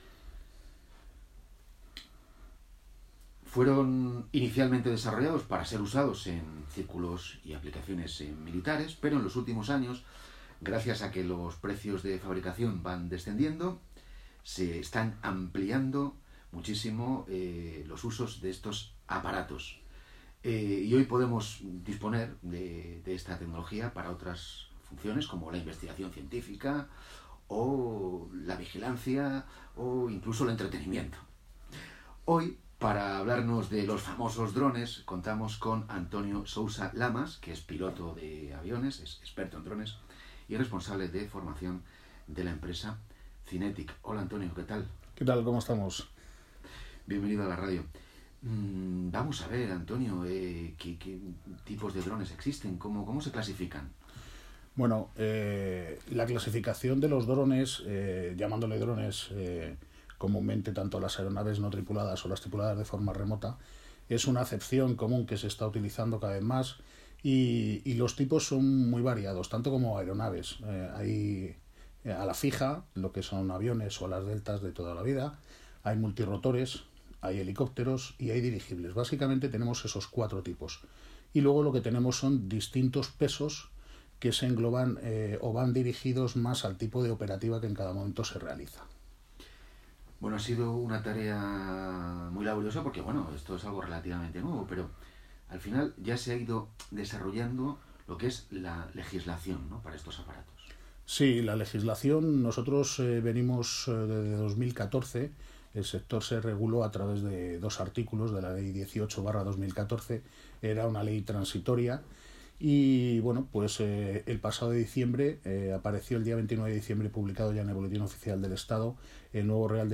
Entrevista RNE.mp3